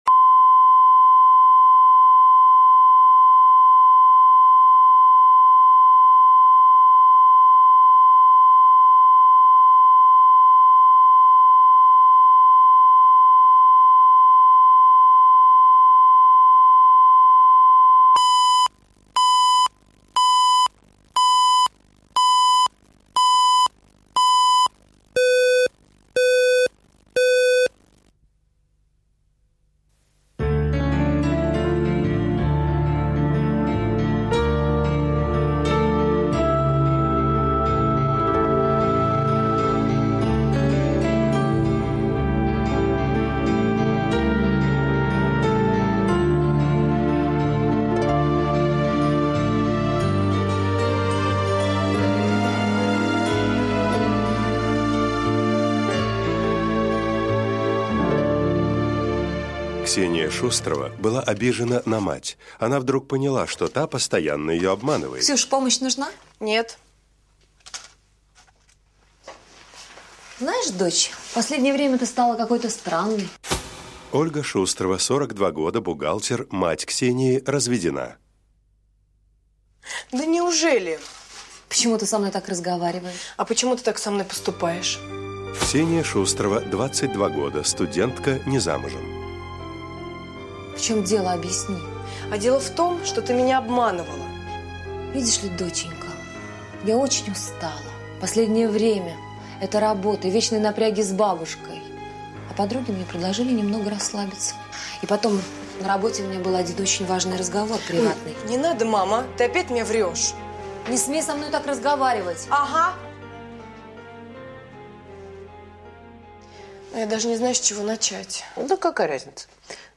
Аудиокнига Заложница | Библиотека аудиокниг